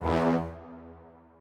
strings4_2.ogg